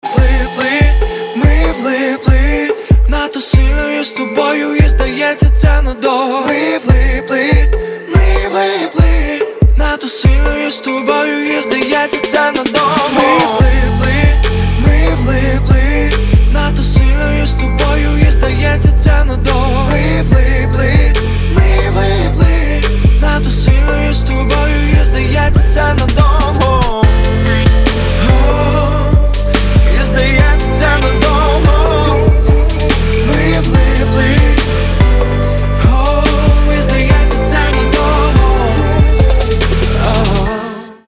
поп
красивые